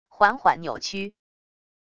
缓缓扭曲wav音频